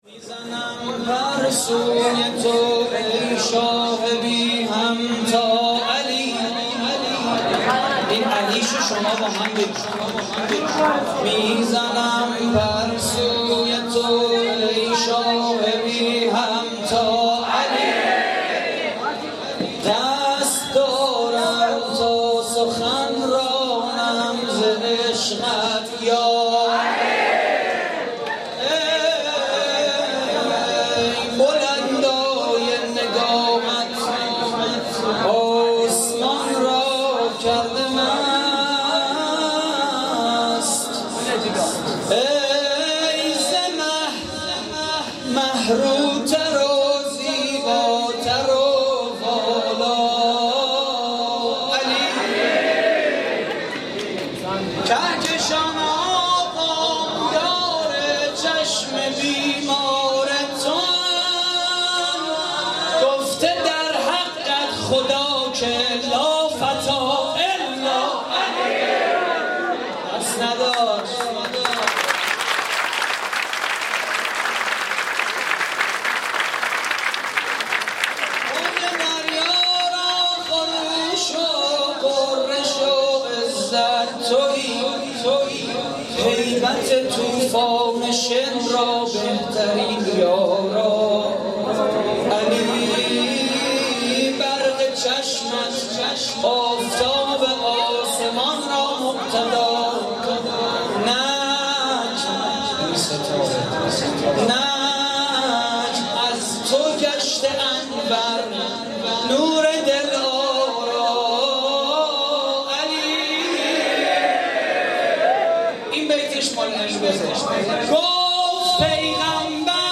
مراسم جشن عید غدیر / اردوی کانون‌های امام رضا (ع) - مشهد مقدس؛ 28 مرداد 98
صوت مراسم:
مدح: می‌زنم پر سوی تو